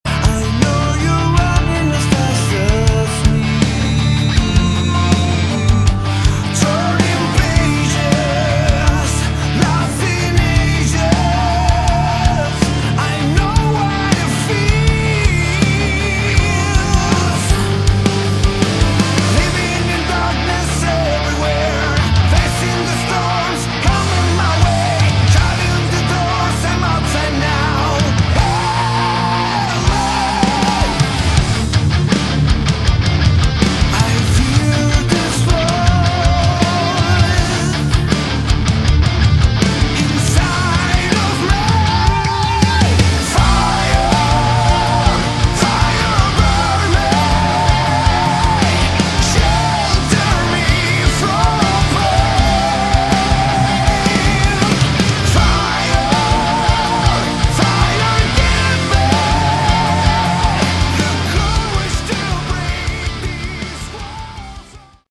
Category: Melodic Metal
vocals
guitar
keyboards, backing vocals
bass
drums